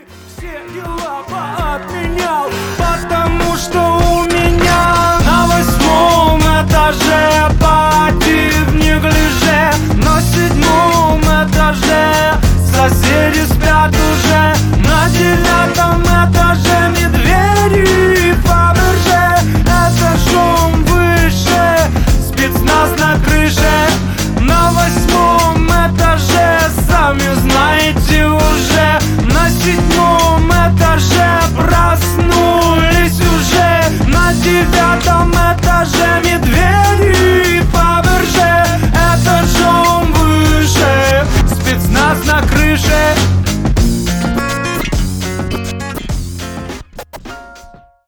• Качество: 247, Stereo
поп
гитара
мужской голос
лирика